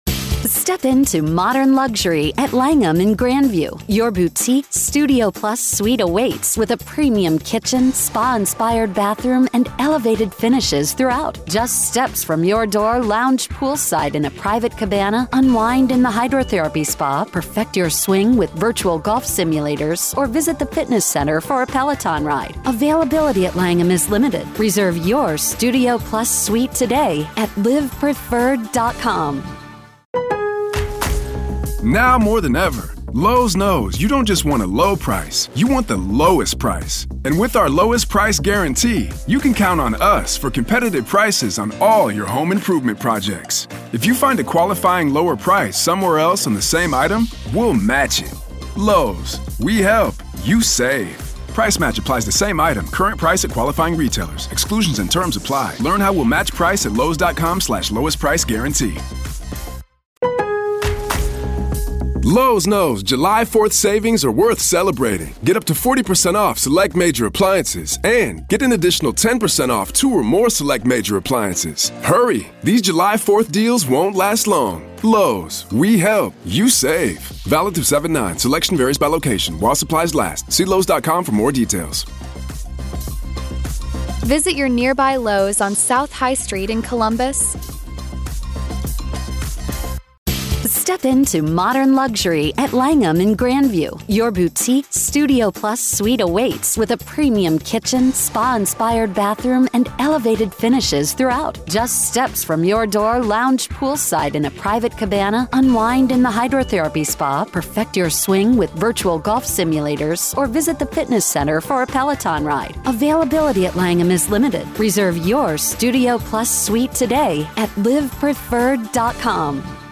Main Points: Chad Daybell's trial might explore claims of delusional thinking, akin to Lori Vallow's defense. The discussion contrasts deeply held religious convictions with clinically recognized delusions.